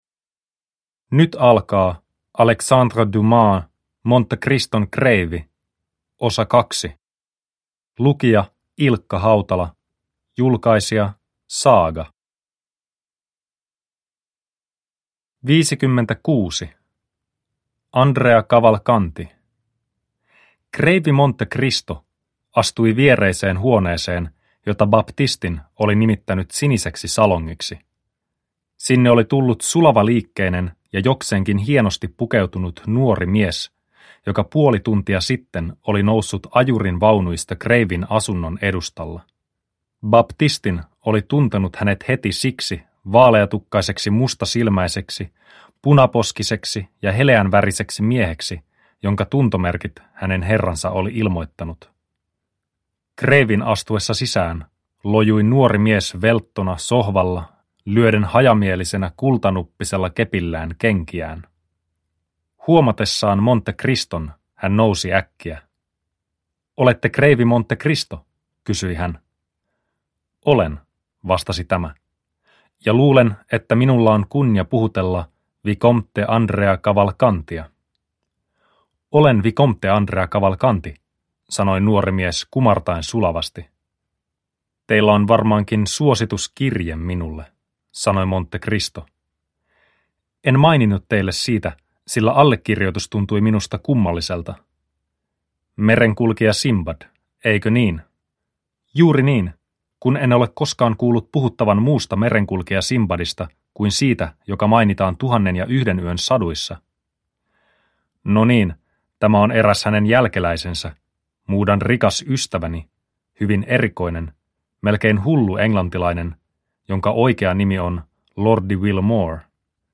Monte-Criston kreivi 2 – Ljudbok